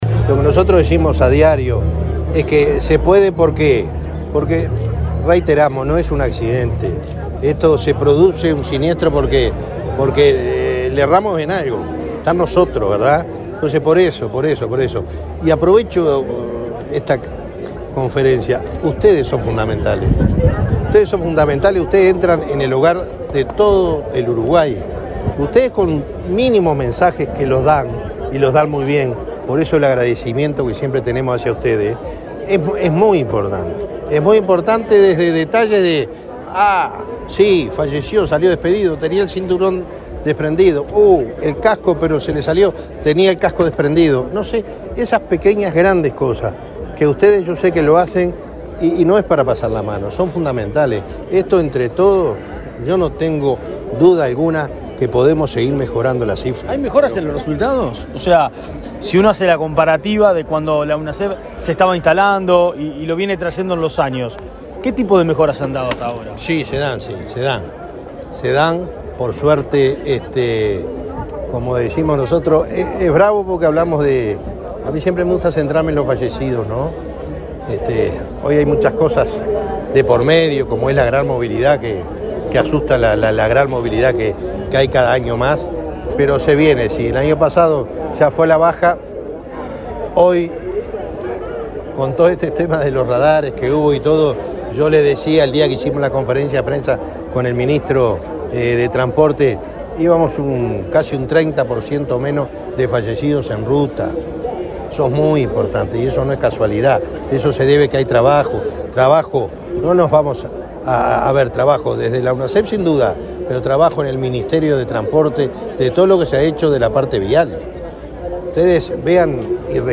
Declaraciones del presidente de Unasev, Alejandro Draper
El presidente de la Unidad Nacional de Seguridad Vial (Unasev), Alejandro Draper, dialogó con la prensa, luego de inauguar el stand de ese organismo